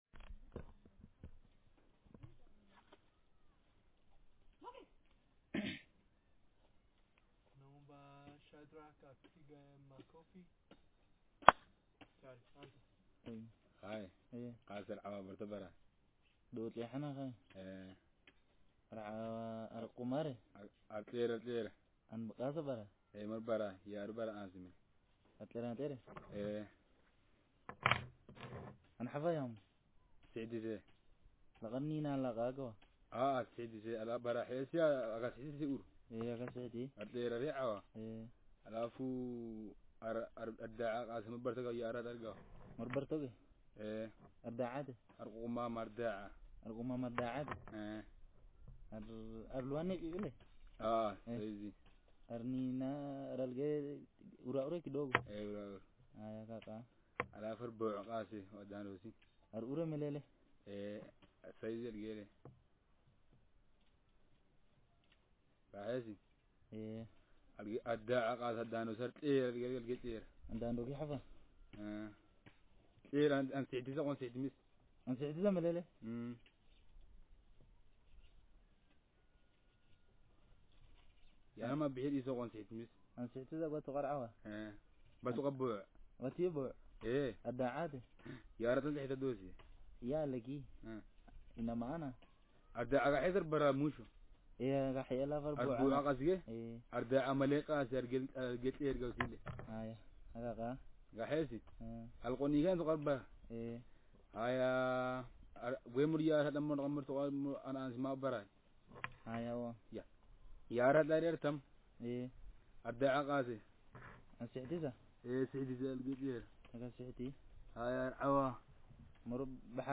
Speaker sex m/m Text genre conversation